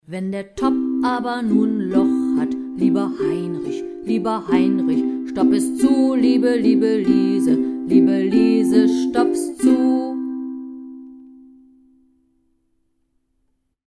für Ukulele in C-Stimmung, Band 1